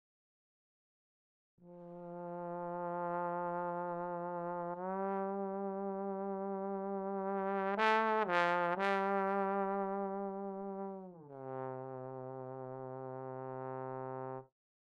Иногда хочется "погудеть")) Вложения тромбос глиссандирующий, SM.mp3 тромбос глиссандирующий, SM.mp3 586,7 KB · Просмотры: 280